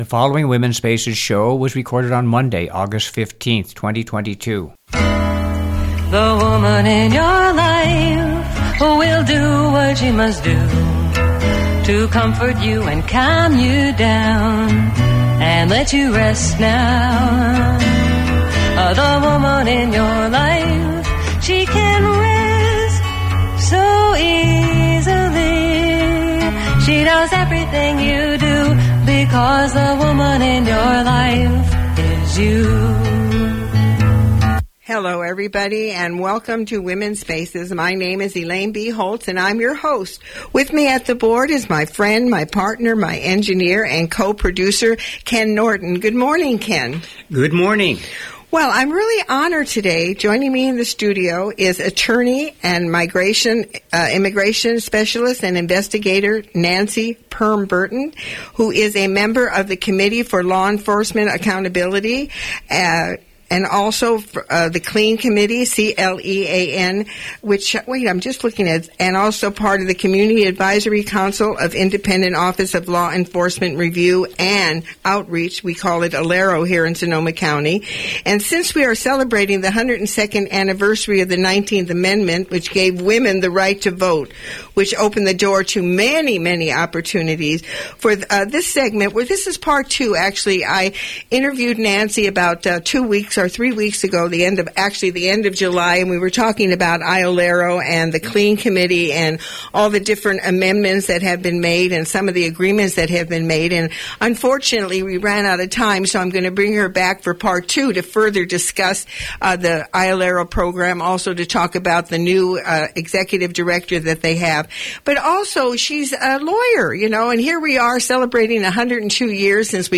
August 15, 2022 Original Radio Show ID: WSA220815 Listen to the Show on the Mp3 Player below